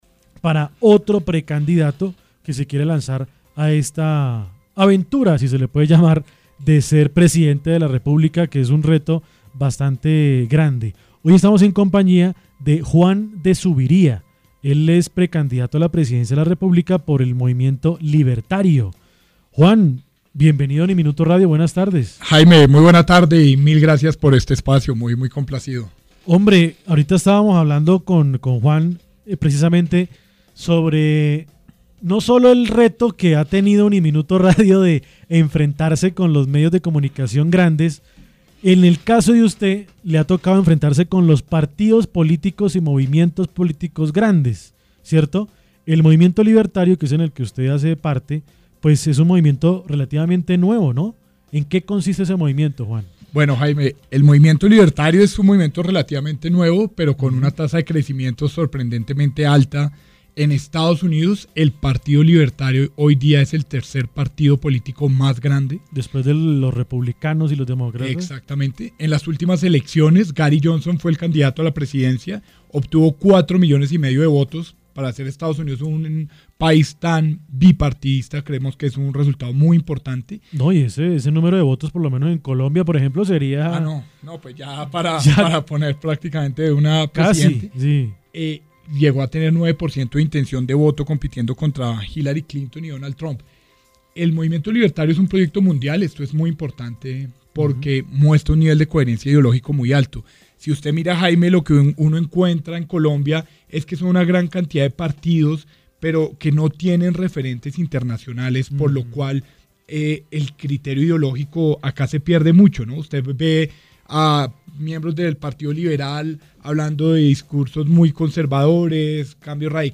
En su visita a la cabina de UNIMINUTO Radio